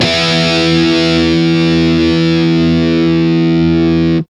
Index of /90_sSampleCDs/Roland L-CDX-01/GTR_Distorted 1/GTR_Power Chords